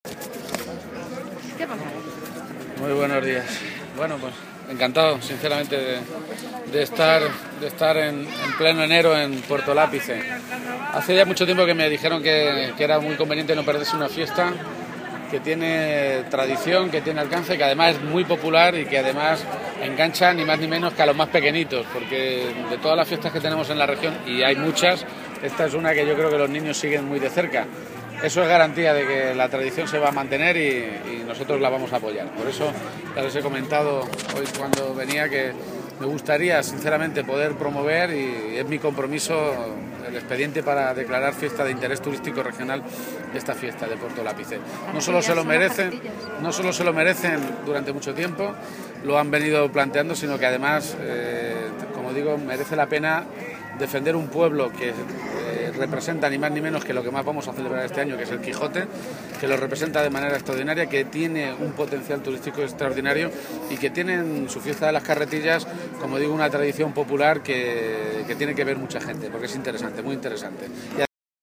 Lo ha hecho en su visita, esta misma mañana, a este municipio manchego, para participar precisamente en esa fiesta, que coincide con San Antón.